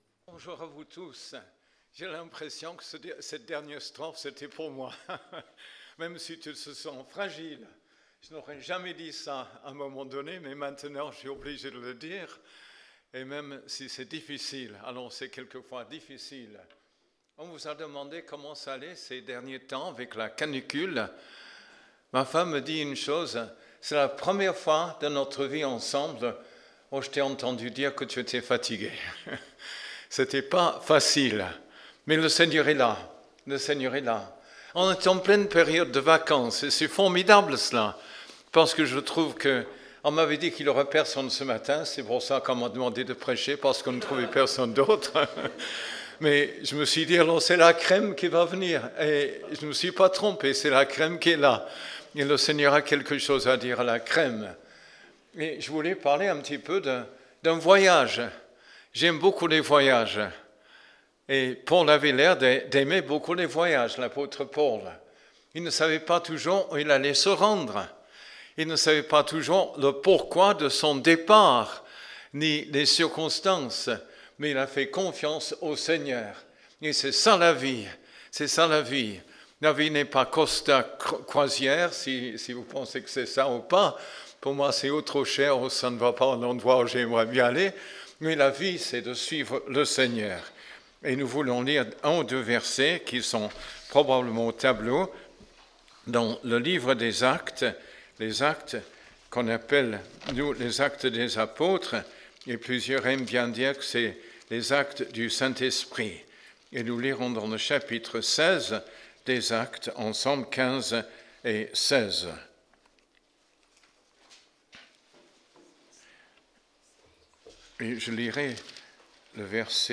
Culte du 26 juillet